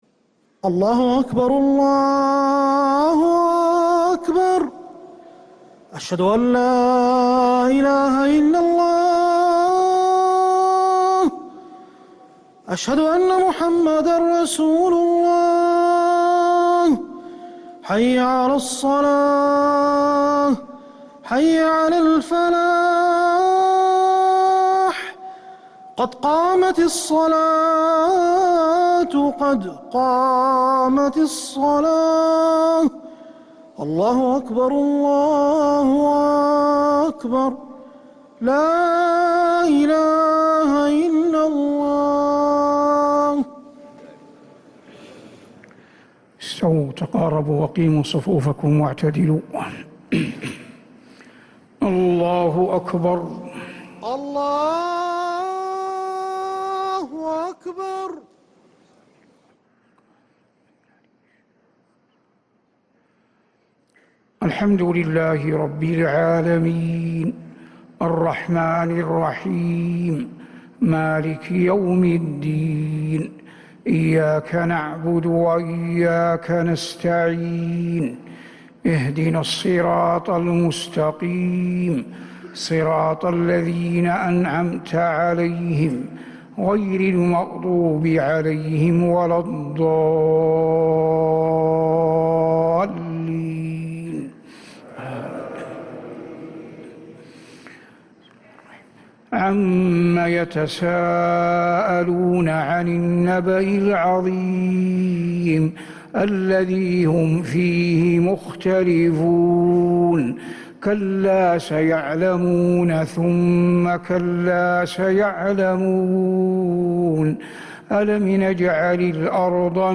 Haramain Salaah Recordings: Madeenah Fajr - 12th April 2026
Madeenah Fajr - 12th April 2026